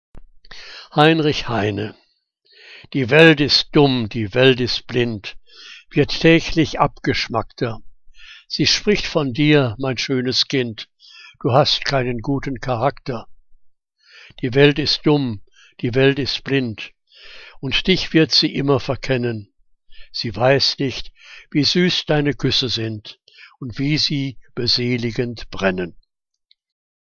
Liebeslyrik deutscher Dichter und Dichterinnen - gesprochen (Heinrich Heine)